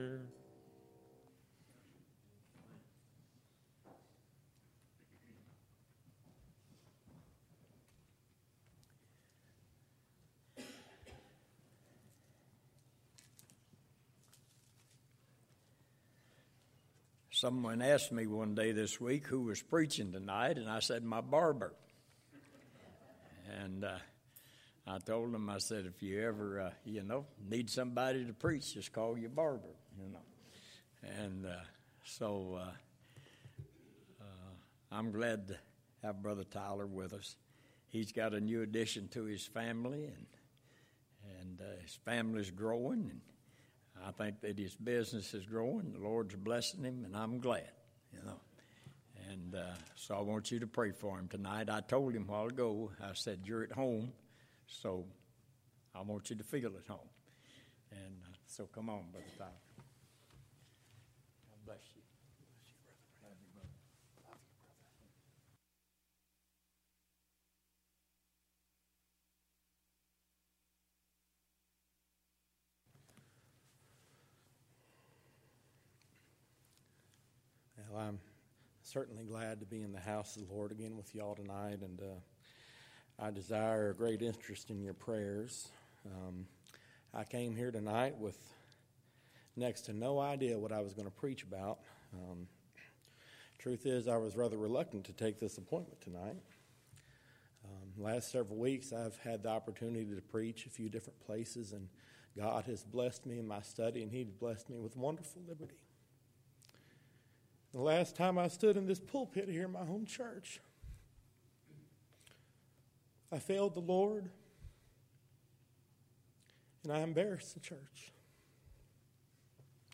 Microphone issues caused a poor recording.
Sermons